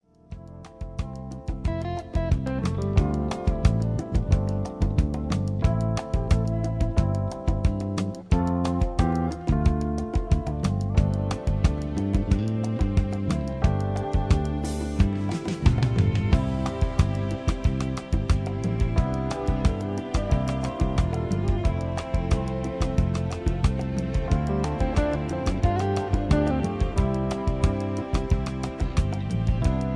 backing tracks